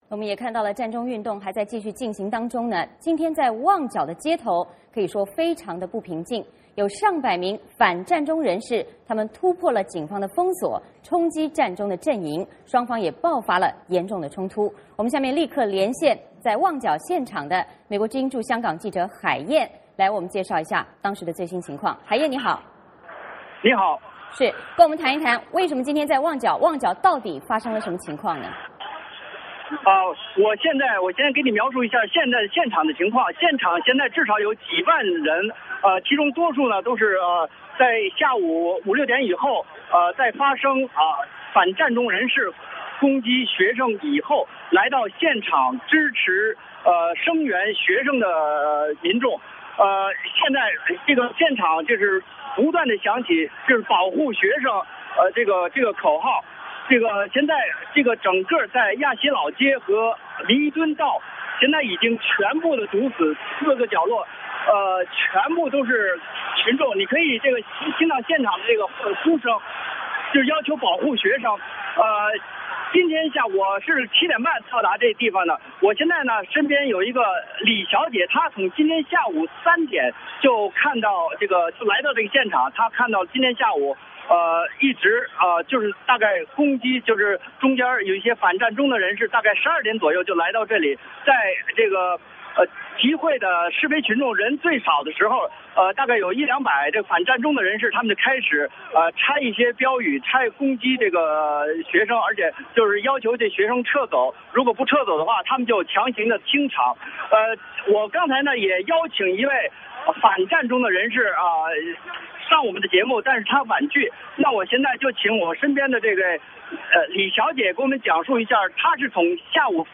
VOA连线：香港旺角占中与反占中人士爆发严重冲突